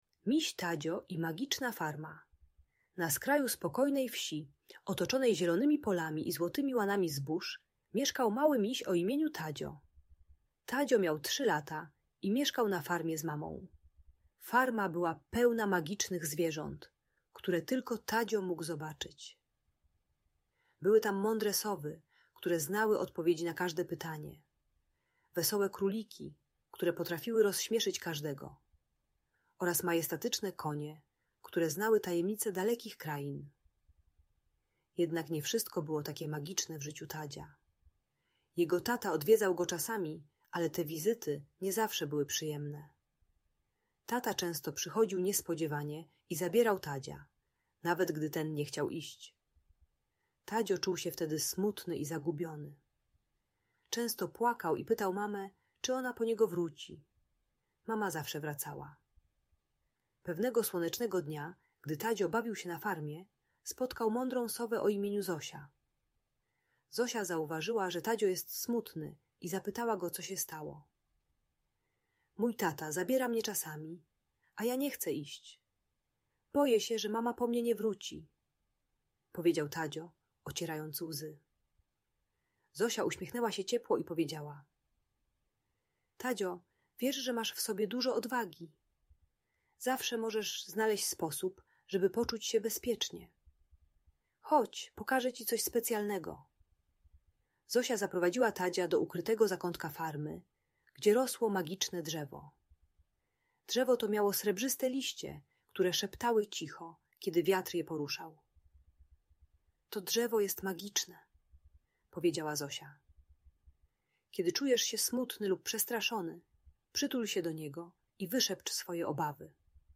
Miś Tadzio na Magicznej Farmie - Audiobajka